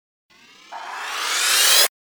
FX-902-WIPE
FX-902-WIPE.mp3